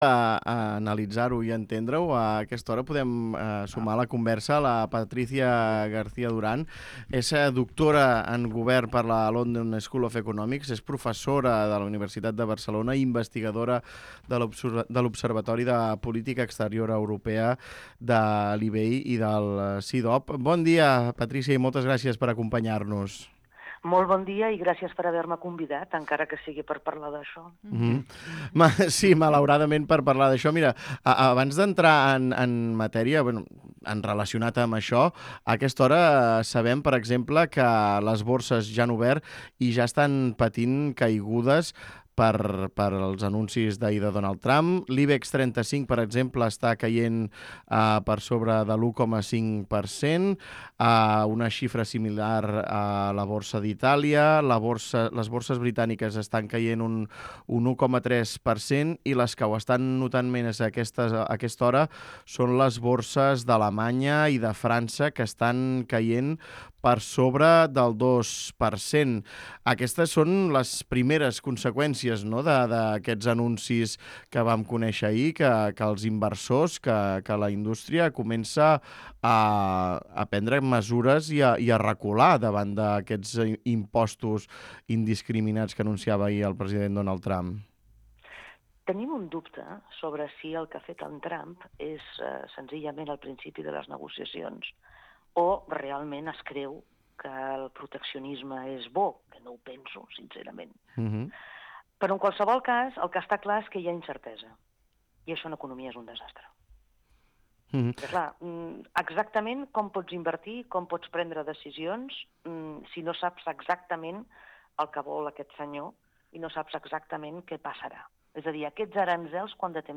Escolta l'entrevista a l'economista